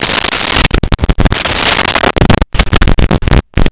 Door Heavy Open Close 02
Door Heavy Open Close 02.wav